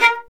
Index of /90_sSampleCDs/Roland L-CD702/VOL-1/STR_Violin 1-3vb/STR_Vln2 % marc